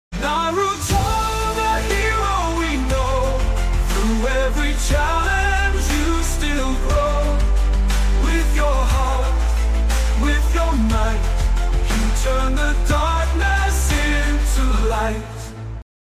Theme song